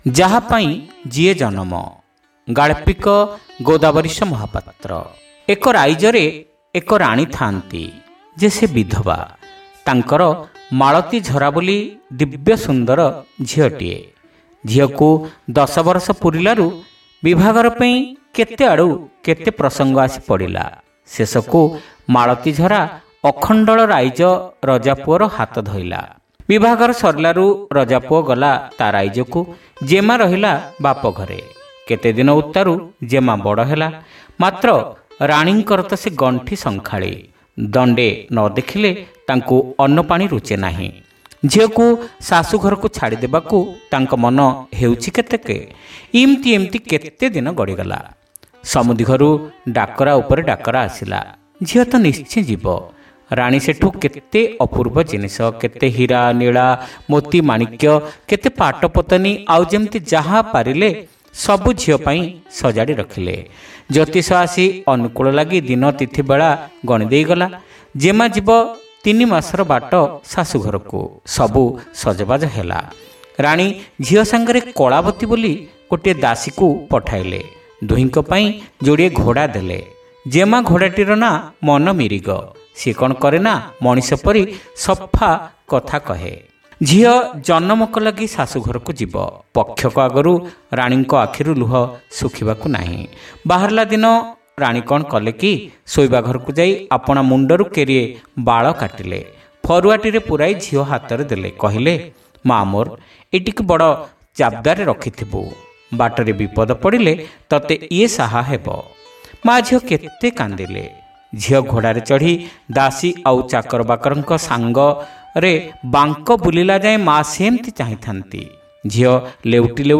Audio Story : Jahapain jie Janama